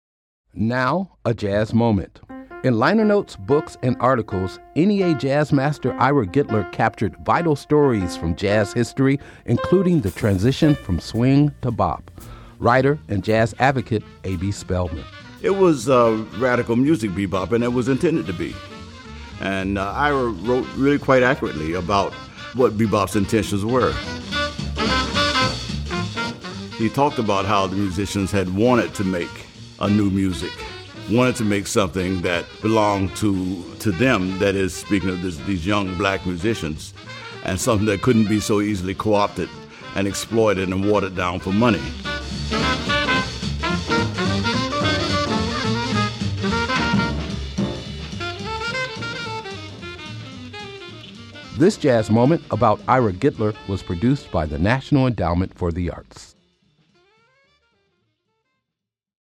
Excerpt of “Bluesville” composed by Samuel Nestico and performed by Count Basie and his Orchestra from the album 88 Basie Street, used courtesy of Concord Music Group and by permission of Fenwood Music Company c/o A Side Music dba Modern Works Music Publishing [ASCAP] and “Dizzy Atmosphere” composed by John Gillespie and performed by Charlie Parker, from the album Bird on 52nd St, used courtesy of Concord Music Group and by permission of Universal Music Corporation [ASCAP].